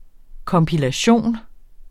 Udtale [ kʌmpiˈlεjɕən ]